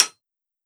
Blacksmith hitting hammer 7.wav